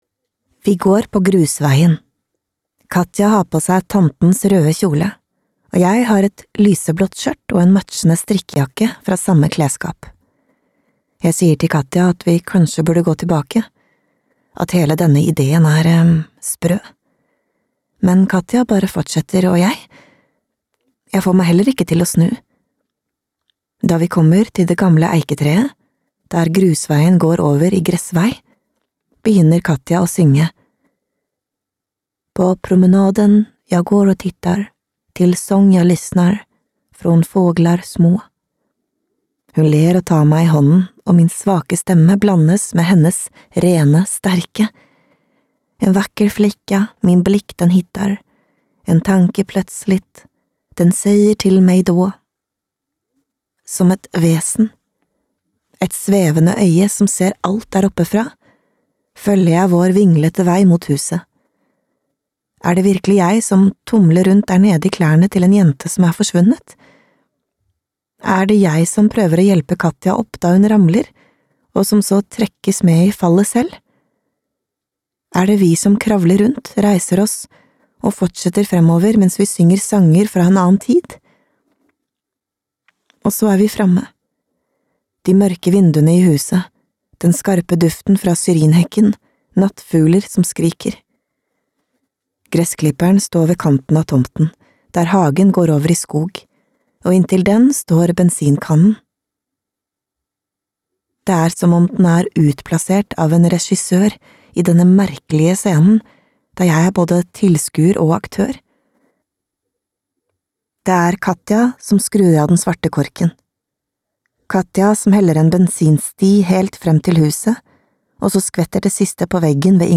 Flammene (lydbok) av Lina Bengtsdotter